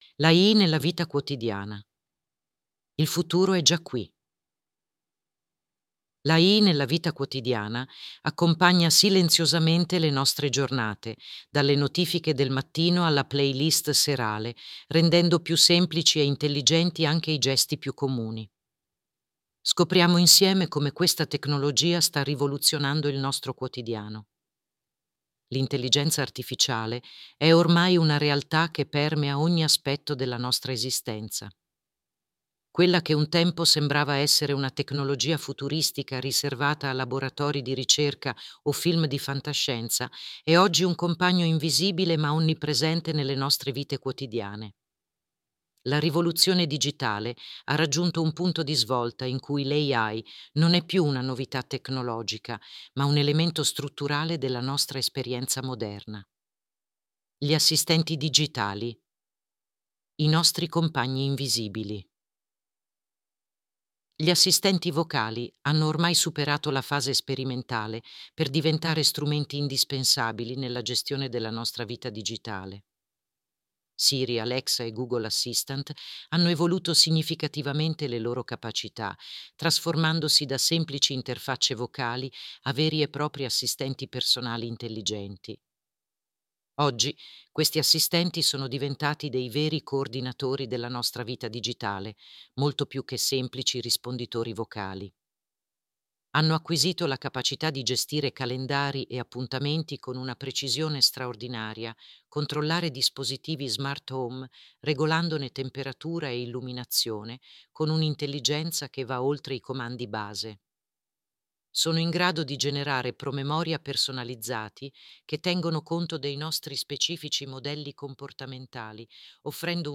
(Audio dell’articolo – voce sintetizzata con ElevenLabs e riprodotta con l’Intelligenza Artificiale – durata: 8 min)